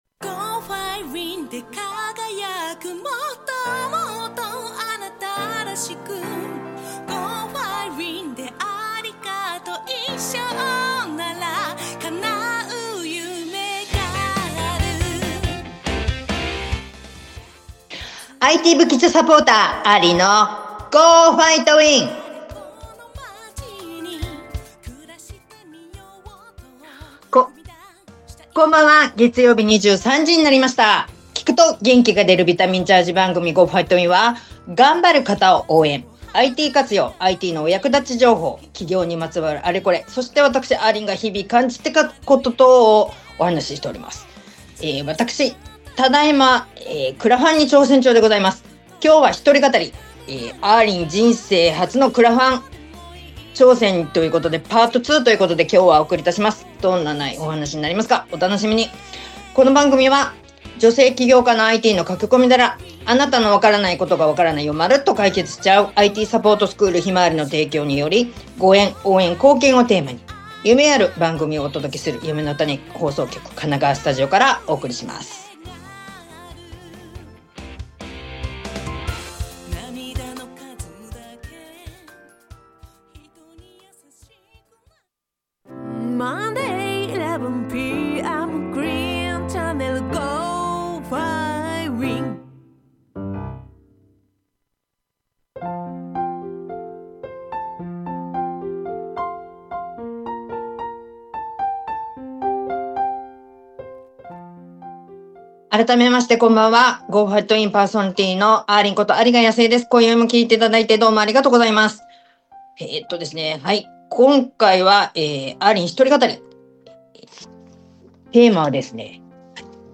今回の投稿では、11/24にオンエアーになりました、ひとりがたりの内容をお送りいたします。この日のテーマは、「クラファン初挑戦についてpart2」です。